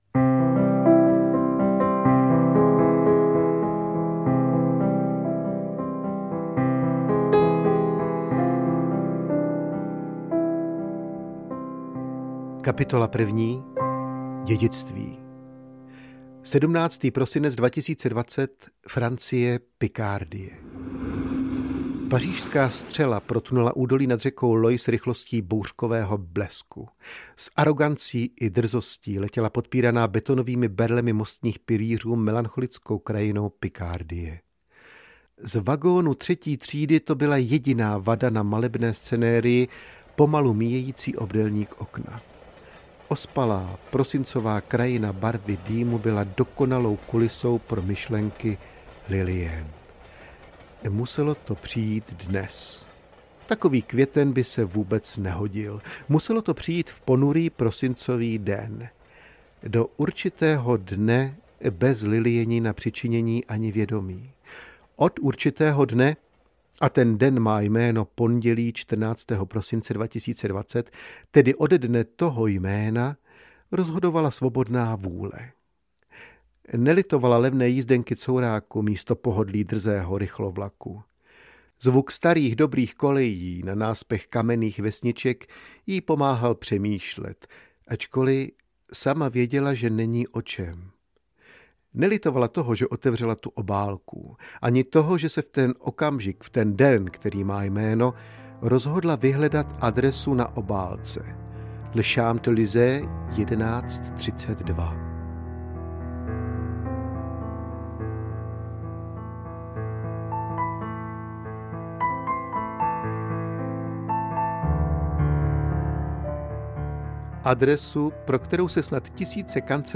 Temný mlýn audiokniha
Ukázka z knihy